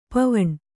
♪ pavaṇ